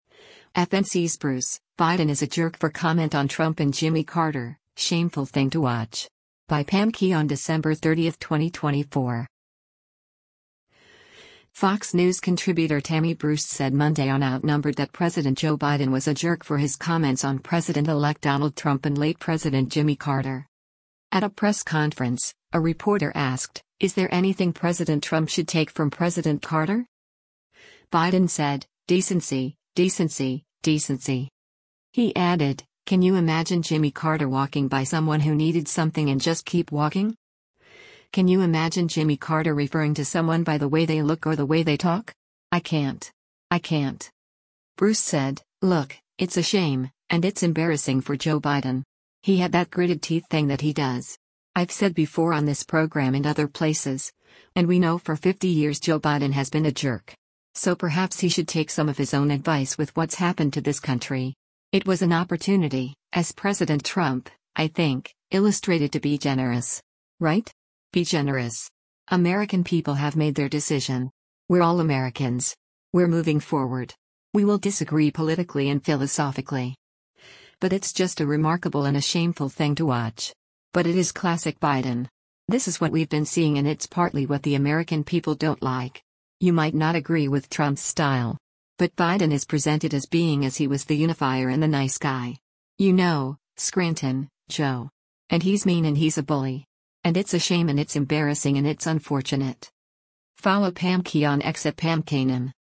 Fox News contributor Tammy Bruce said Monday on “Outnumbered” that President Joe Biden was a “jerk” for his comments on President-elect Donald Trump and late President Jimmy Carter.
At a press conference, a reporter asked, “Is there anything President Trump should take from President Carter?”